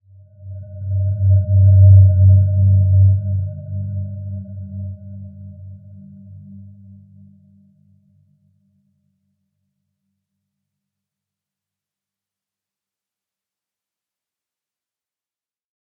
Dreamy-Fifths-G2-p.wav